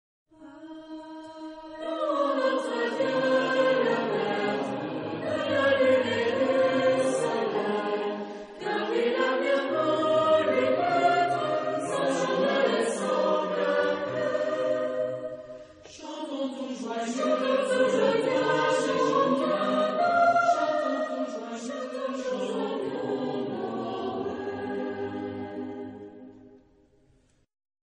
Genre-Style-Form: Partsong ; Folk music ; Christmas song ; Secular
Type of Choir: SATB  (4 mixed voices )
Tonality: A major